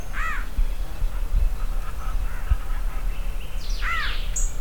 contact call ssp paraguayensis recorded Laguna Capitán, Cuenca Upper Yacaré Sur
Thamnophiluscaerulescenschacossp.wav